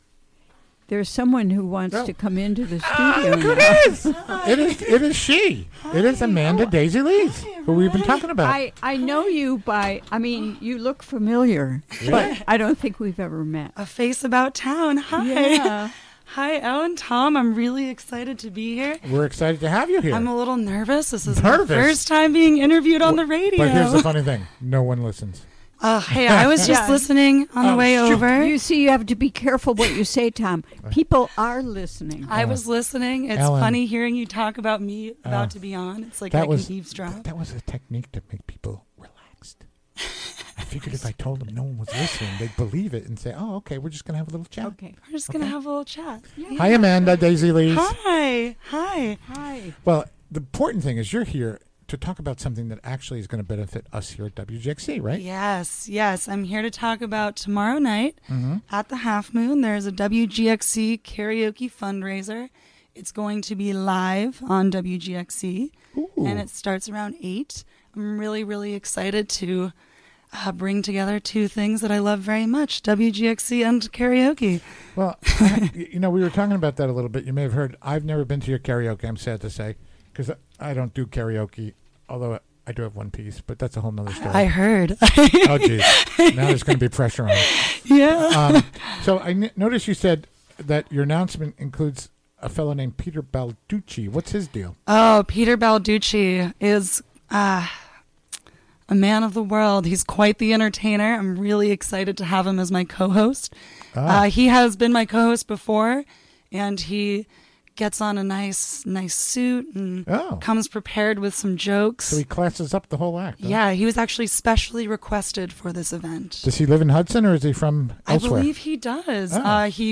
Recorded live during the WGXC Afternoon Show Thursday, May 24, 2018.